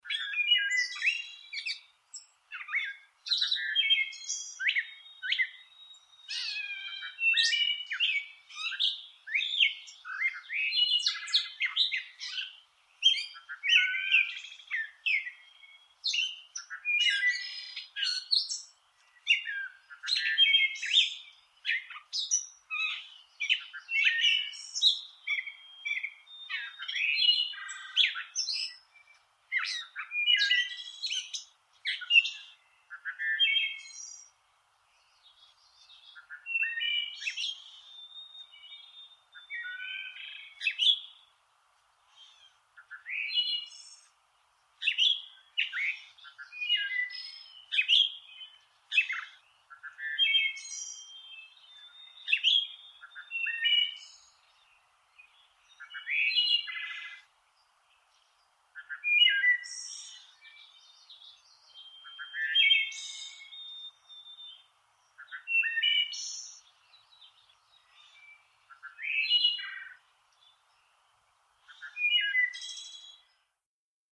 Catbird and redwing blackbird ( New York State )